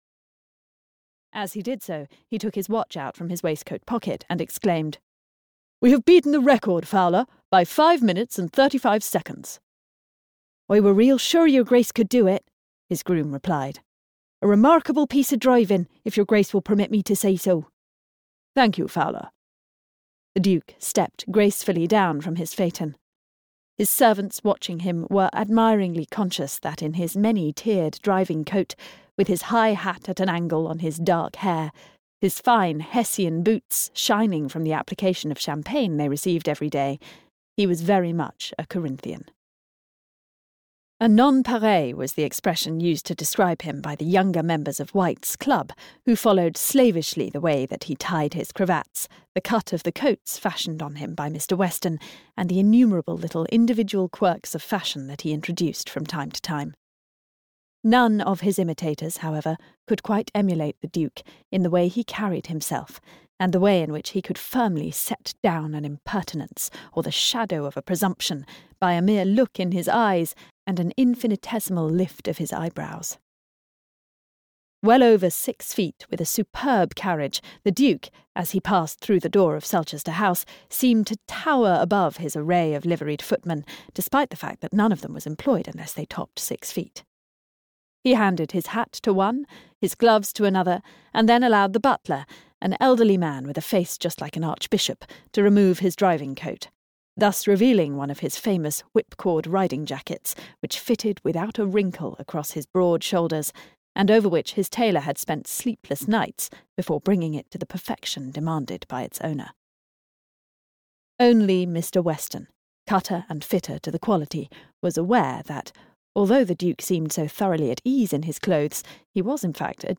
The Odious Duke (EN) audiokniha
Ukázka z knihy